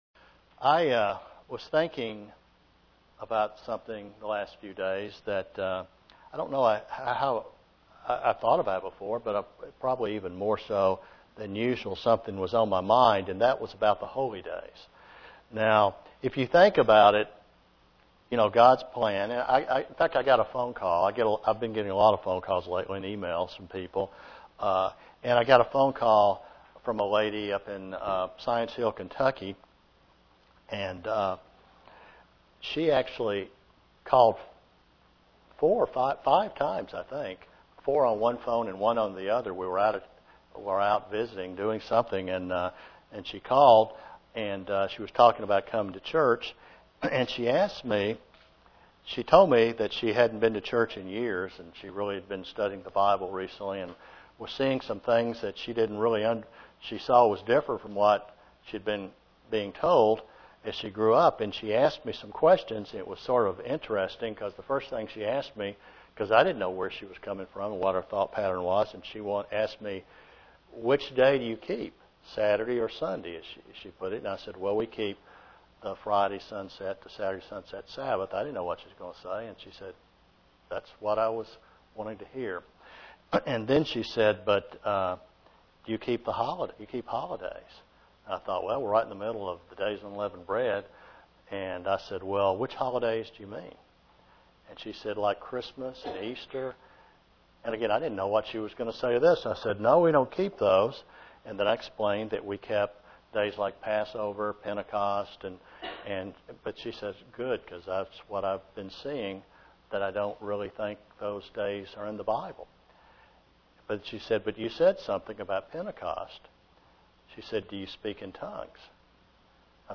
The book of James is a pivotal book for a Christian as they live a life of striving to put out sin. There are many lessons for a Christian as they learn to obey God. (Presented to the Knoxville, TN church)